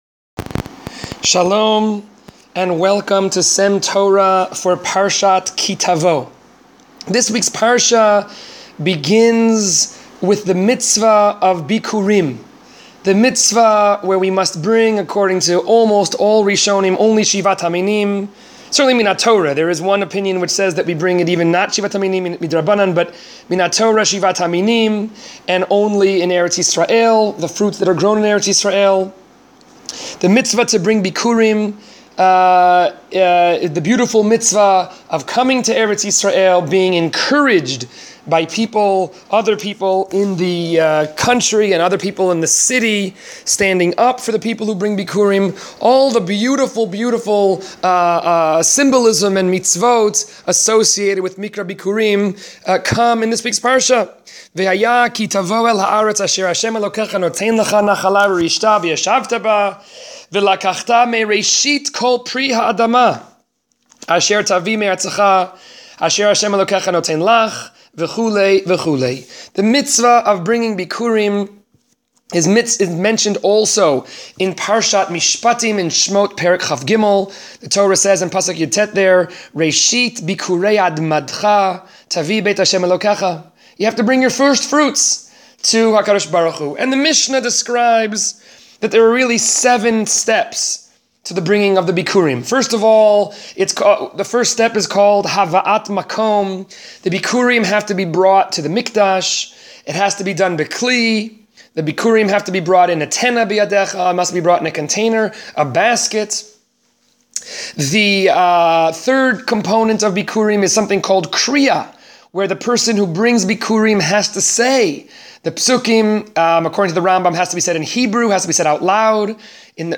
S.E.M. Torah is a series of brief divrei Torah delivered by various members of the faculty of Sha�alvim for Women.